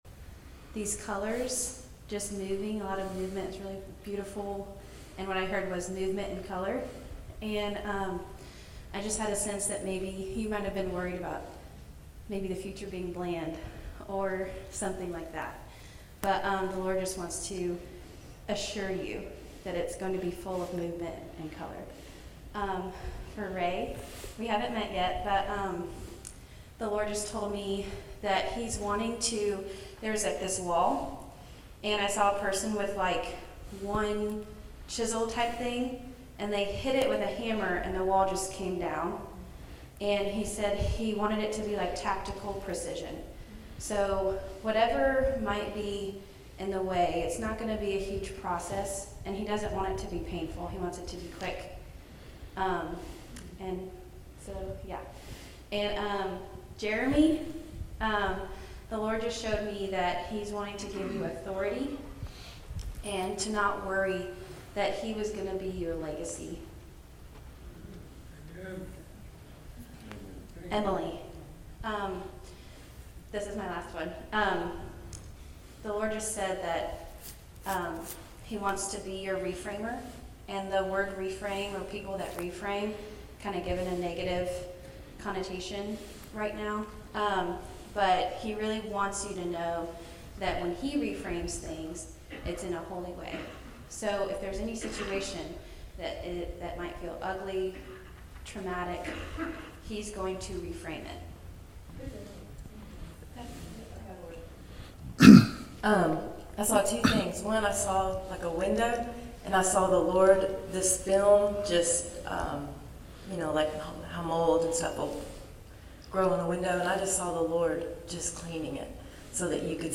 Genesis 4:25-26 Service Type: Devotional